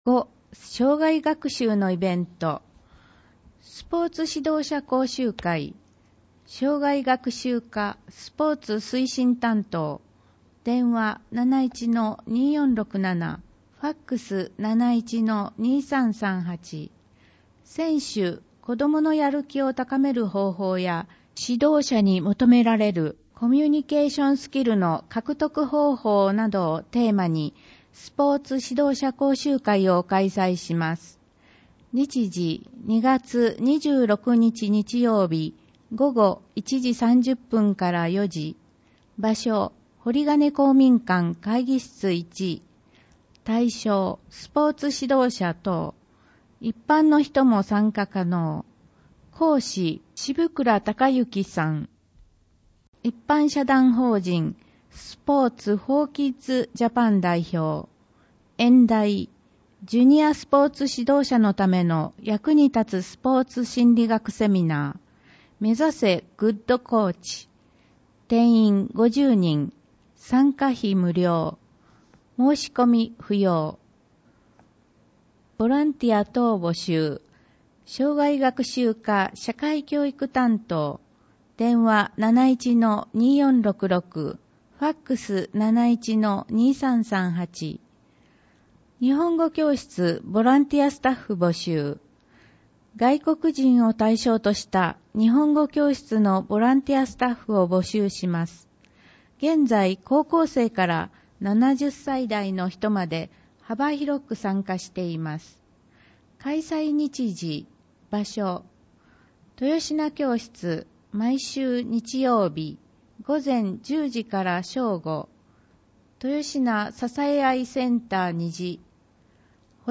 広報あづみの朗読版244号（平成29年2月15日発行) - 安曇野市公式ホームページ
「広報あづみの」を音声でご利用いただけます。この録音図書は、安曇野市中央図書館が制作しています。